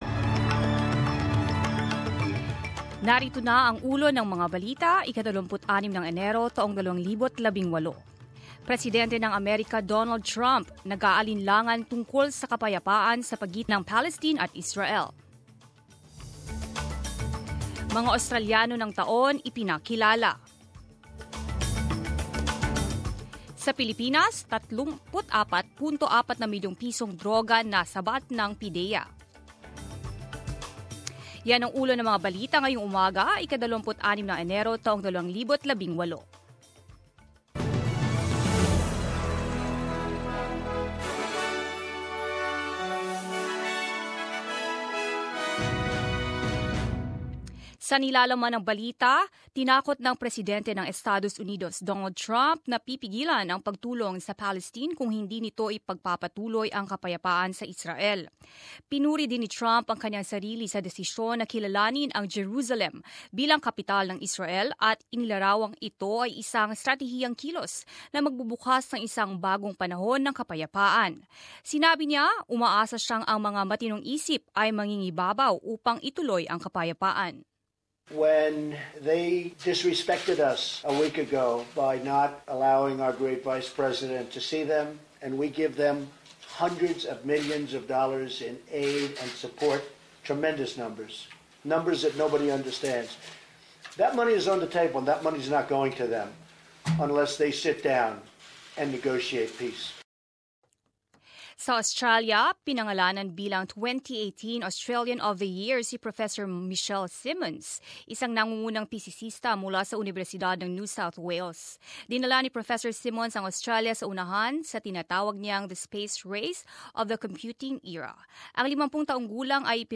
Balita ngayon ika-26 Enero